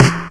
• Clean Snare C Key 30.wav
Royality free snare one shot tuned to the C note. Loudest frequency: 1086Hz
clean-snare-c-key-30-K5l.wav